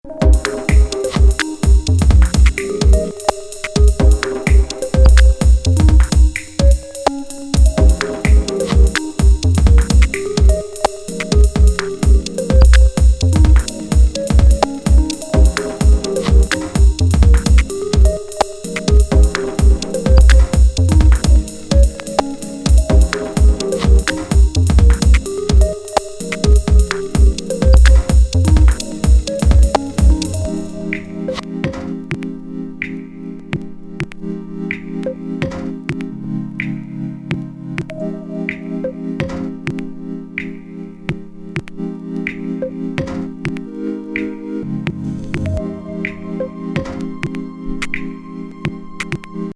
佳曲A面!ビートを抑えたチルアウトVER.のB面もクール。